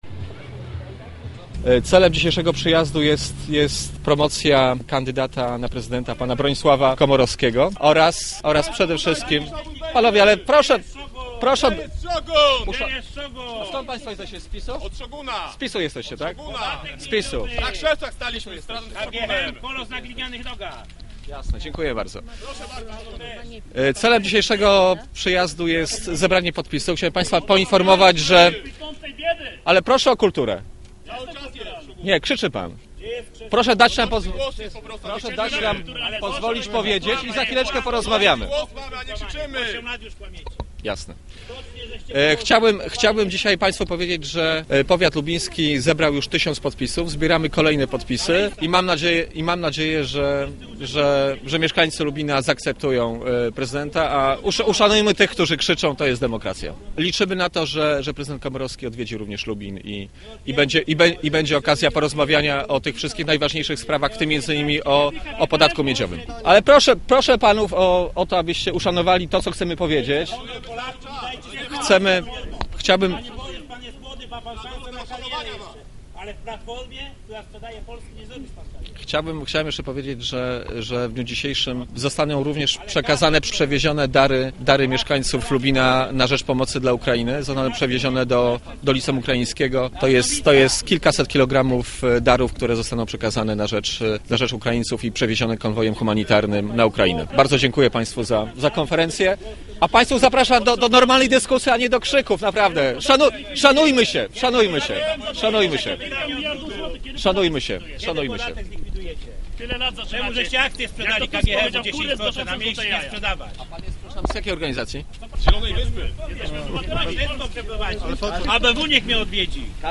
Przemówienie byłego europosła, a dzisiaj doradcy ministra spraw zagranicznych, co chwilę zakłócane było przez grupkę osób - przeciwników prezydentury Bronisława Komorowskiego.
Konferencja prasowa przerodziła się w ostrą wymianę zdań z przecwinikami prezydenta RP.